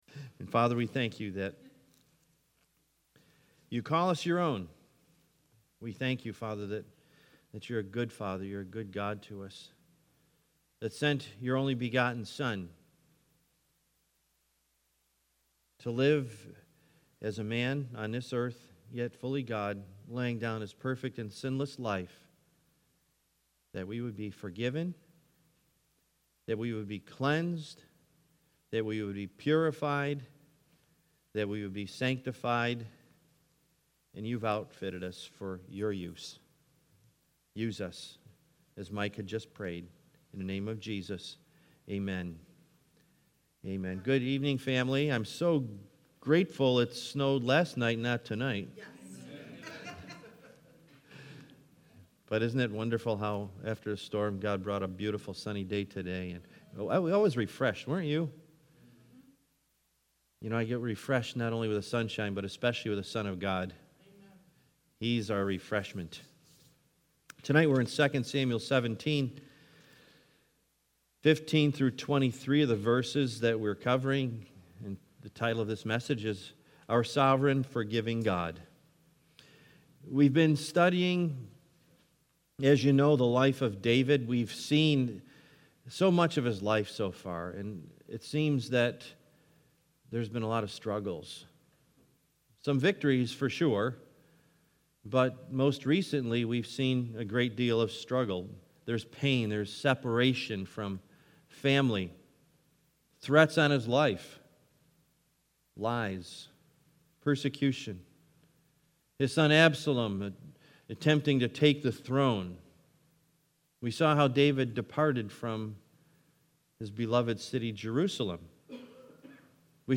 Series: Wednesday Bible Study